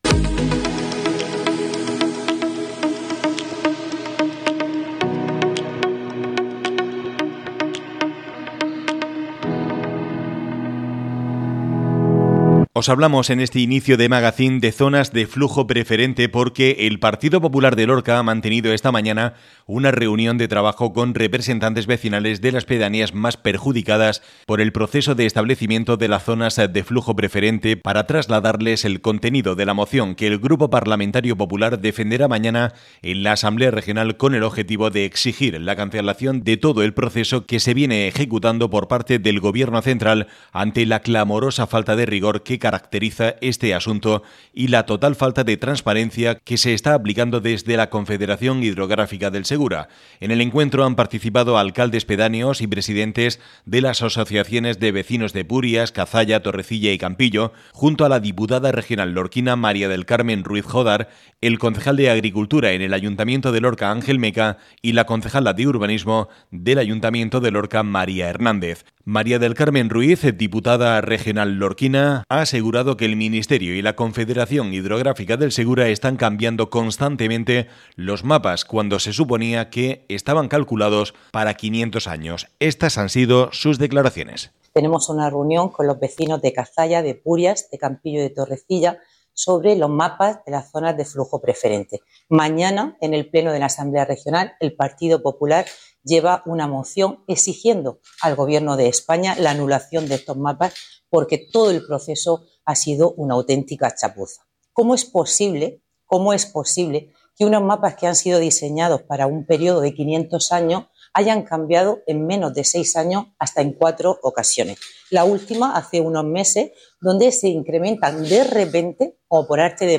Escucha aquí la noticia sobre este tema que tanto preocupa a los vecinos de varias pedanías del término municipal de Lorca.